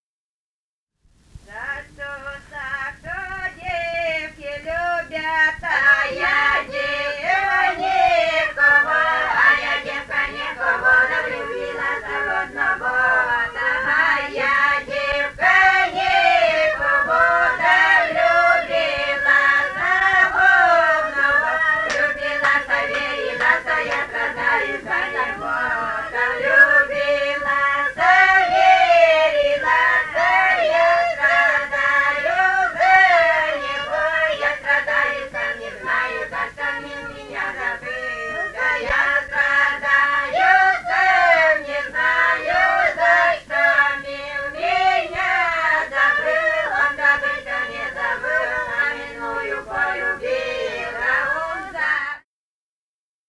Этномузыкологические исследования и полевые материалы
«За что Сашу девки любят» (плясовая).
Костромская область, с. Воскресенское Островского района, 1964 г. И0789-01